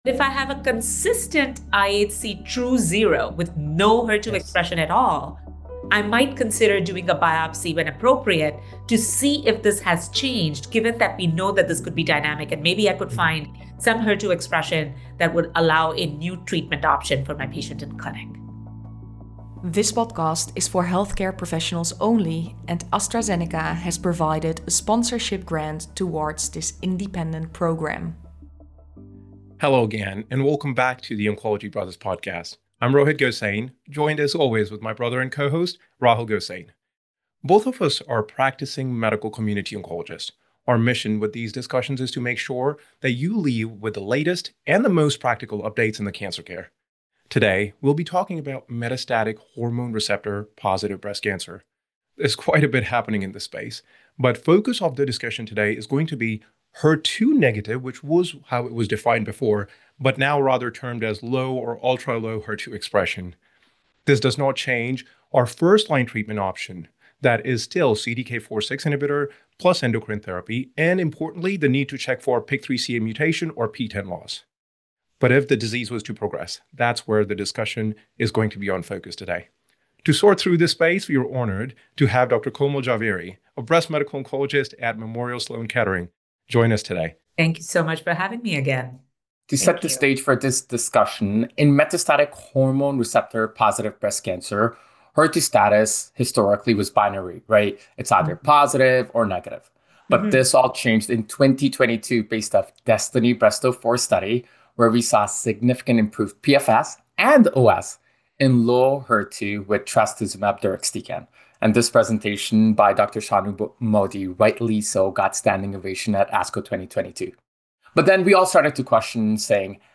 Oncology Brothers (Moderators), US
If you are able, we encourage you to watch the video podcast or listen to the audio, which include emotion and emphasis that is not so easily understood from the words on the page.